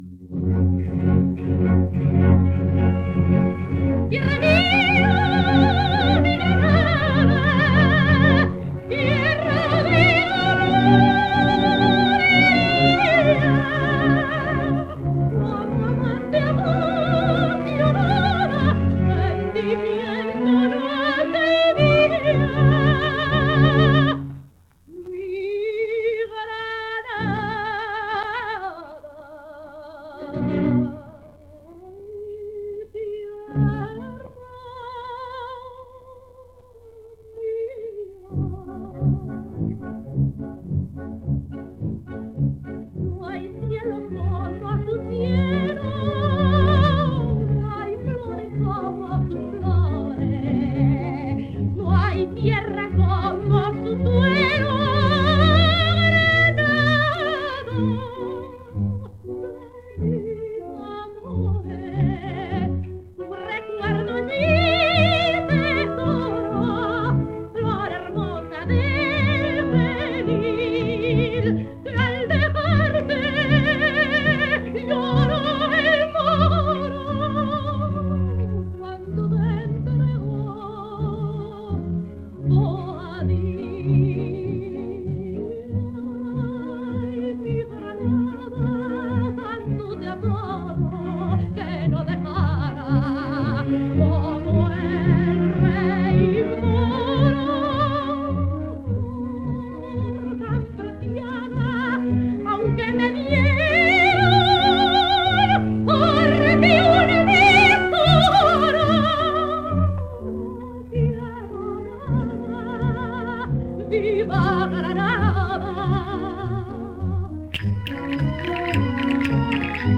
Canción a Granada (madrigal) ("Tierra mía, mi Granada...").
orquesta, director Jacinto Guerrero [78 rpm, Odeón]